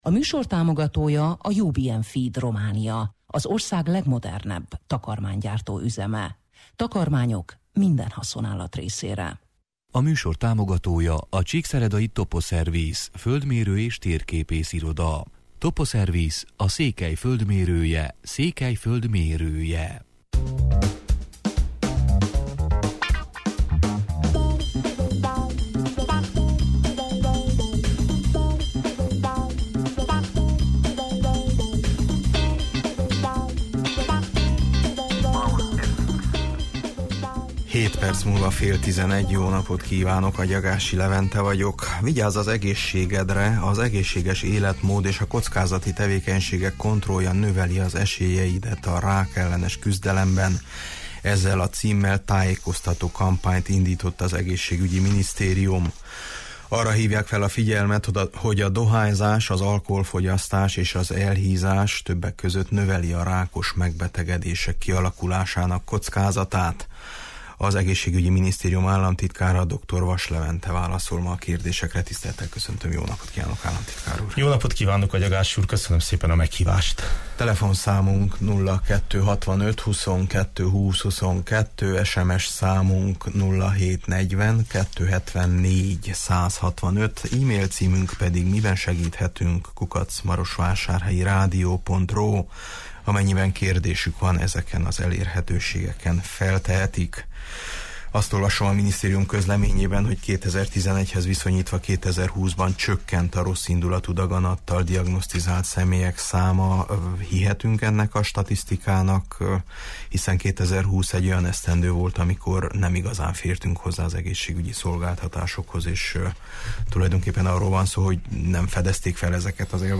Az Egészségügyi Minisztérium államtitkárával, Dr. Vass Leventével beszélgetünk: